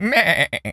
pgs/Assets/Audio/Animal_Impersonations/goat_baa_calm_04.wav at master
goat_baa_calm_04.wav